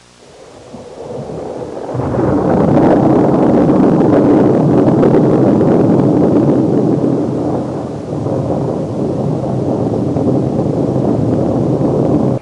Rolling Thunder Sound Effect
Download a high-quality rolling thunder sound effect.
rolling-thunder.mp3